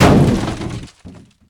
car-metal-impact-5.ogg